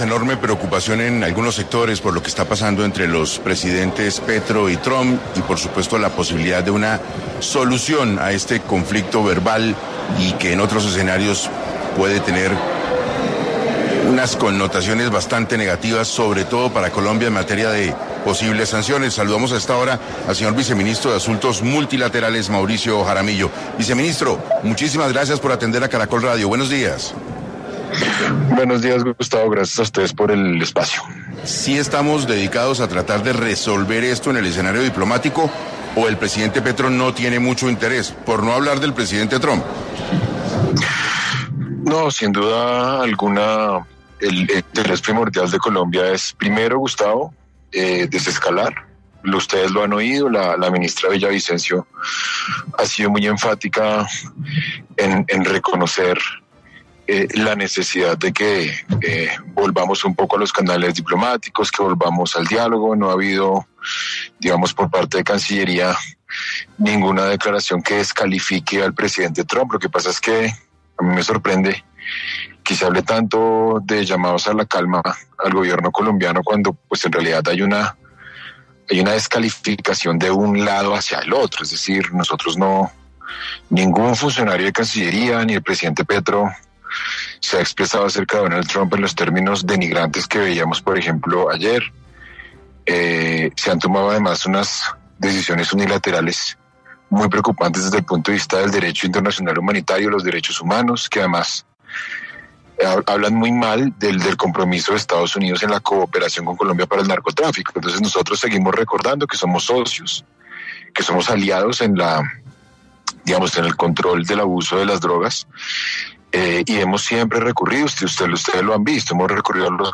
Mauricio Jaramillo Jassir, viceministro de Asuntos Multilaterales de la Cancillería colombiana, estuvo en 6AM para abordar la situación bilateral entre Colombia y Estados Unidos.